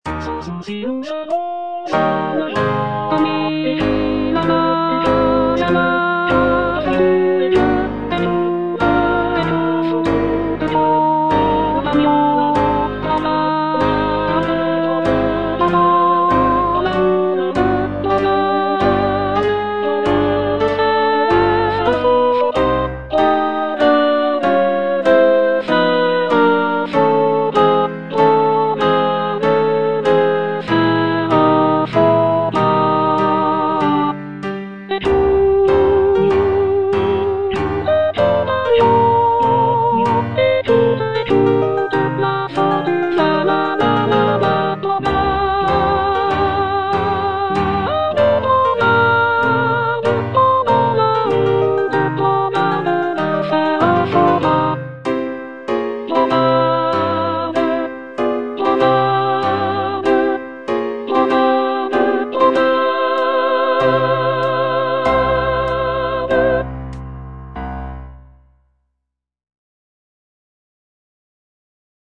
G. BIZET - CHOIRS FROM "CARMEN" Ami, là-bas est la fortune (soprano II) (Voice with metronome) Ads stop: auto-stop